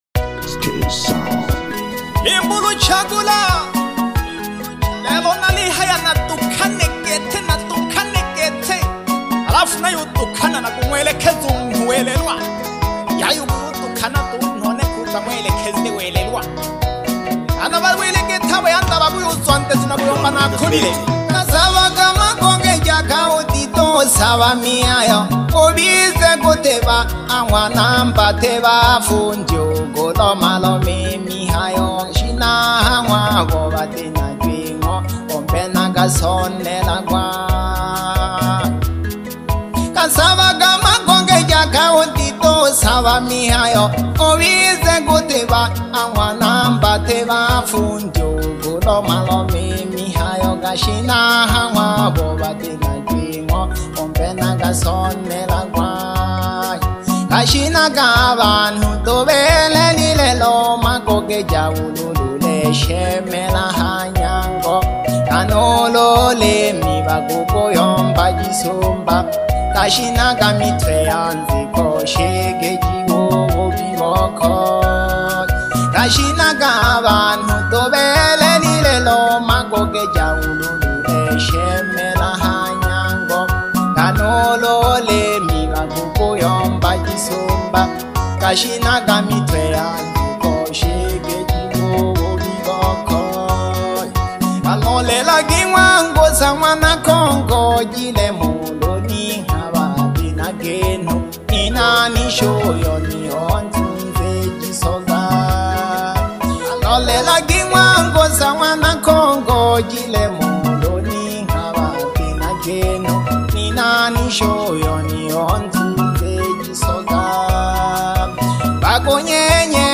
Asili music